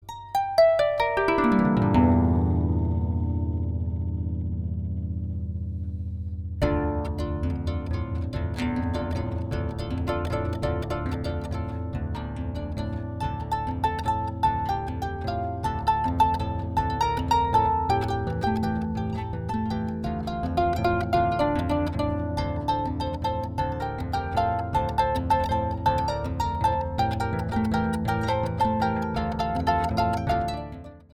Latin & South American